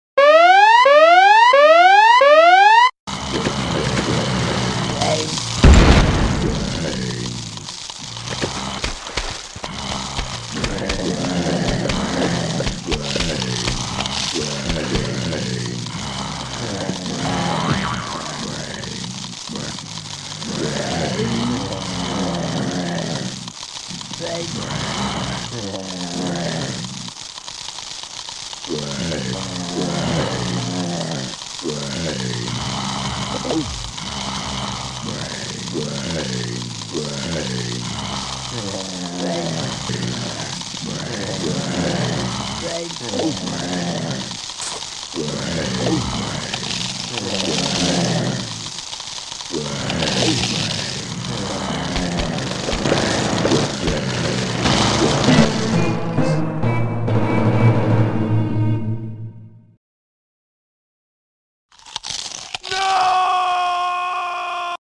ZOMBIES eating sounds